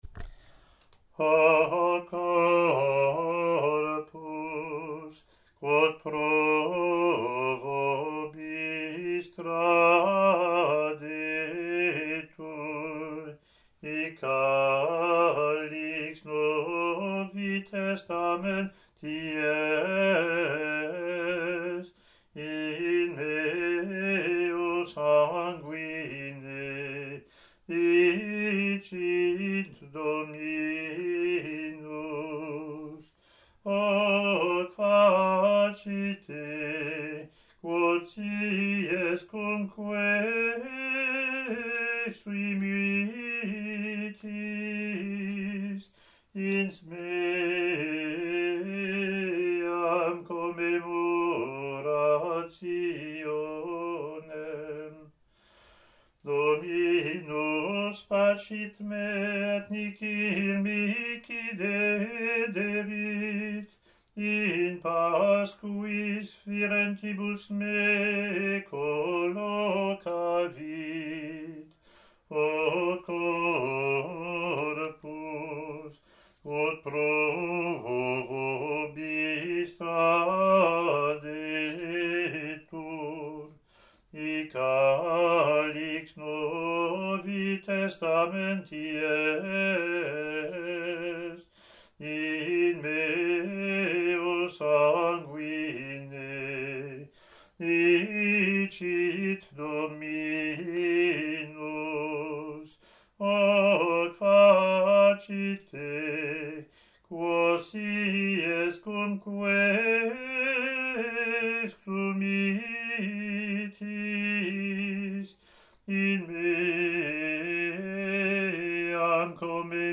Year C Latin antiphon + verses)